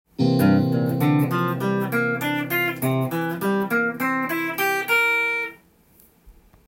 ７ｔｈ　ⅡーⅤ　コード例
C7（Gm7/C7）